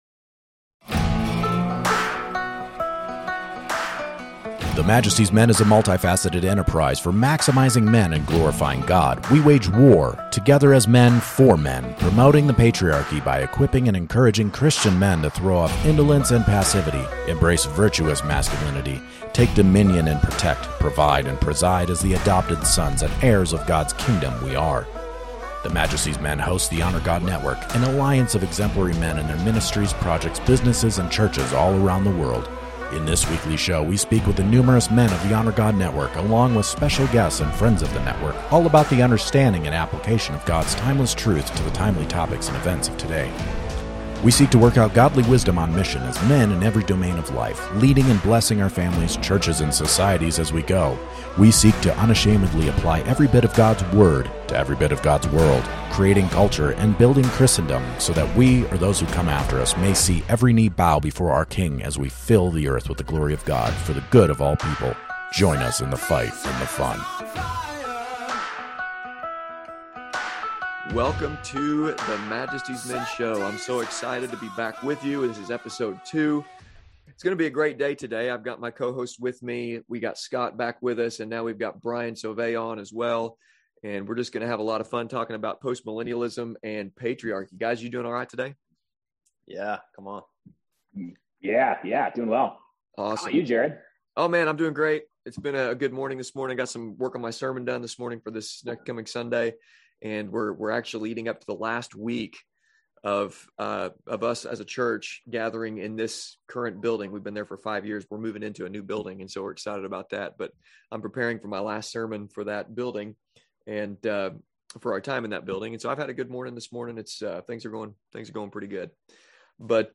The men talk about two topics which have been surging among men recently, much to the surprise of many.